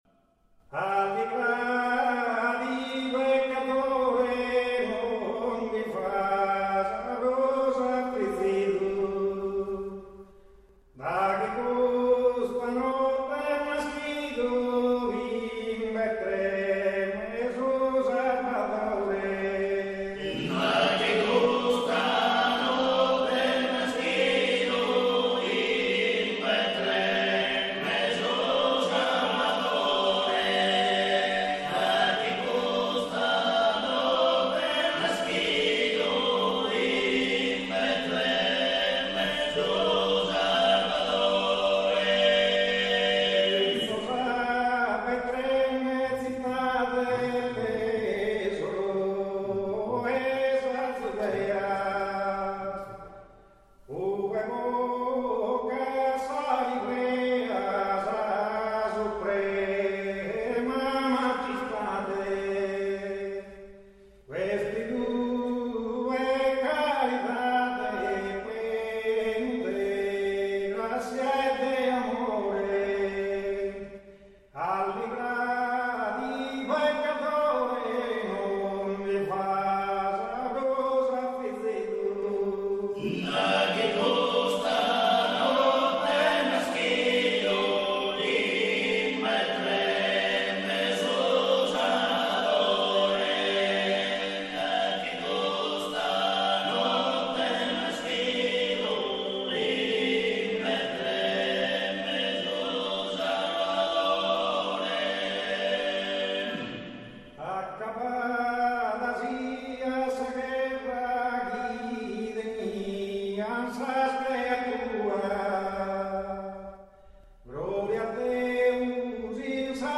Gosos de Nadale - Chiesa San Gavino - Gavoi